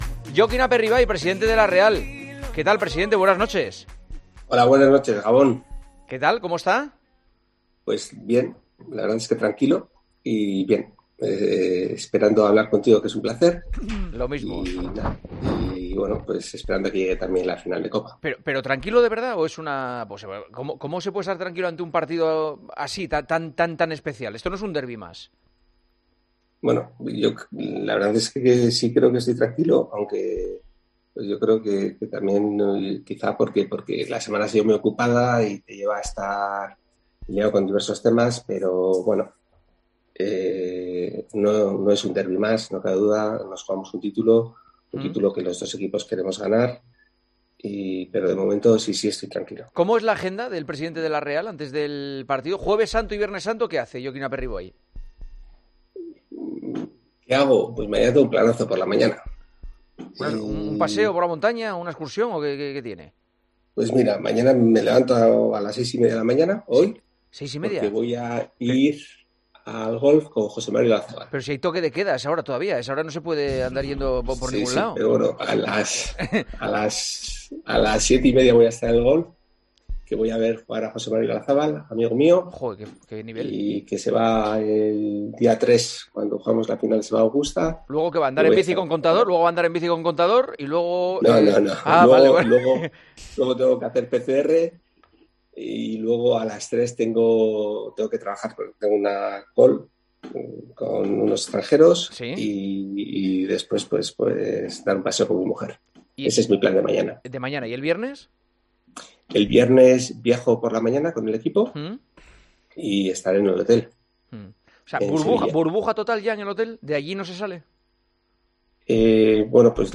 charlaron, desde sus casas